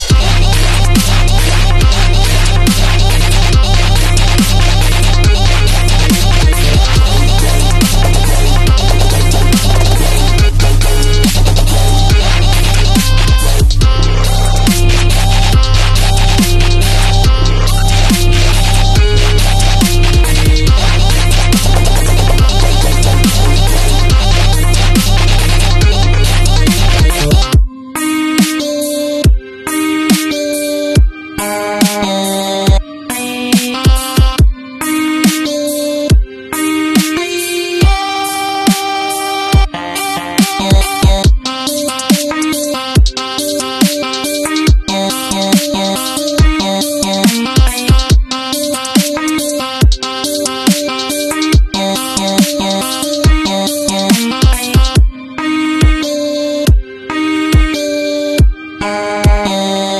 mix dialogue